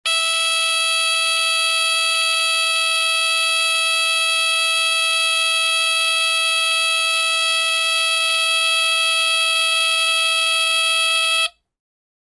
Звуки датчика дыма
На этой странице собраны звуки датчика дыма — от резкого тревожного сигнала до прерывистого писка.